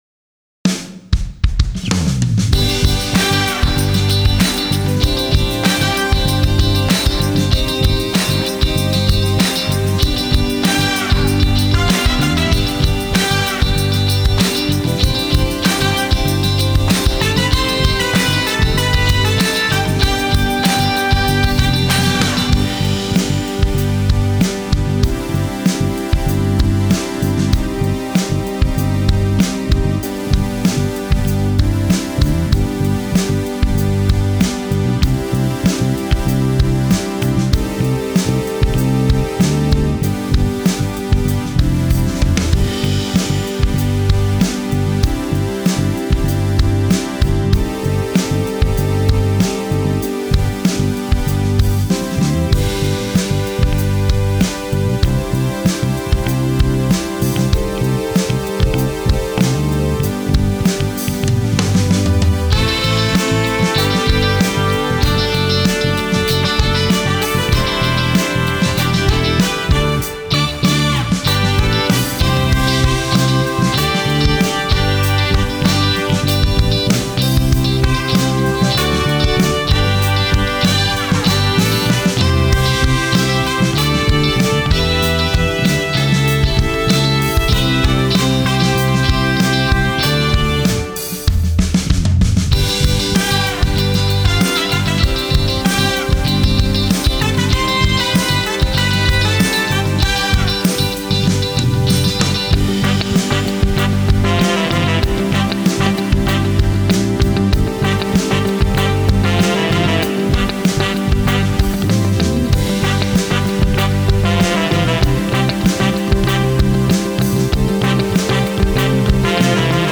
Key: G BPM: 96 Time sig: 4/4 Duration:  Size: 12.2MB
Gospel Worship